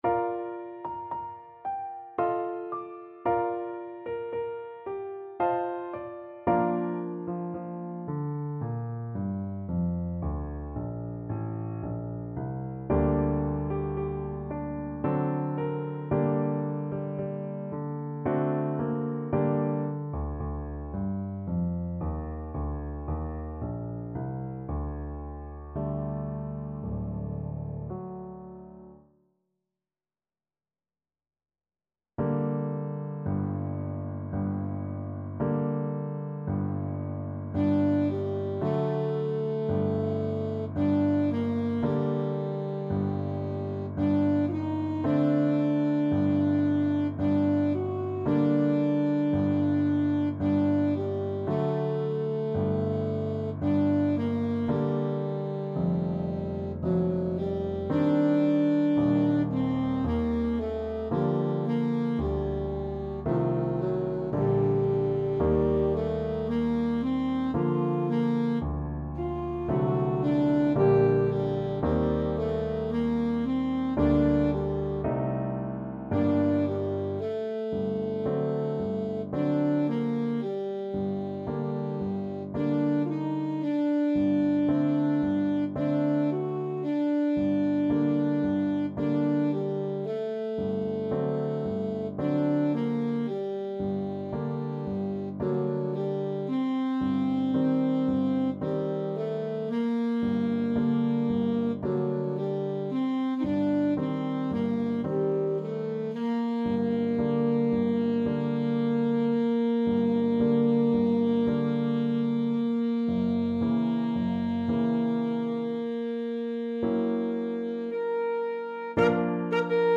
Classical Tchaikovsky, Pyotr Ilyich Serenade melancolique, Op.26 Alto Saxophone version
Alto Saxophone
Bb major (Sounding Pitch) G major (Alto Saxophone in Eb) (View more Bb major Music for Saxophone )
3/4 (View more 3/4 Music)
~ = 56 Andante
Classical (View more Classical Saxophone Music)
tchaik_serenade_melancolique_ASAX.mp3